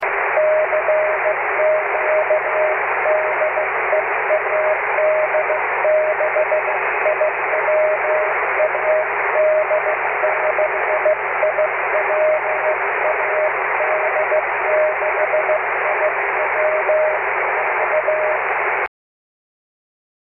19th May - Dubus EME Contest CW/SSB